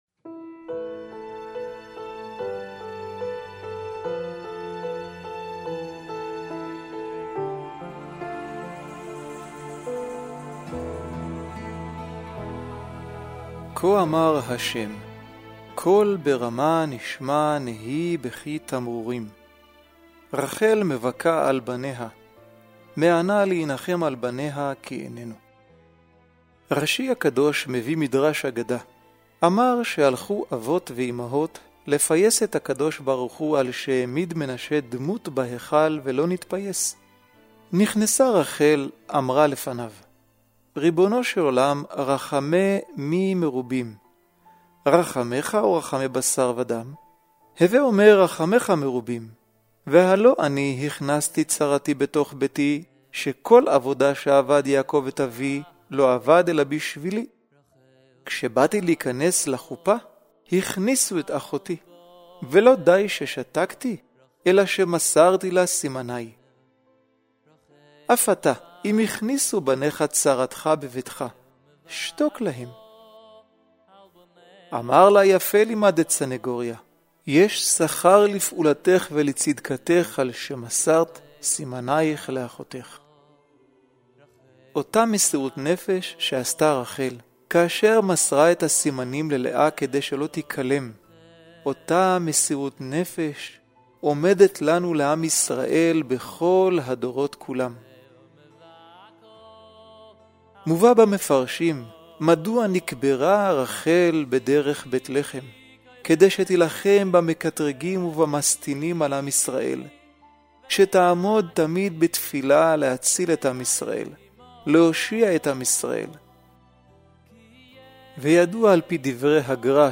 קריינות ועריכה דיגיטאלית
למי שנצרך לקרינות מצרף קטע לדוגמא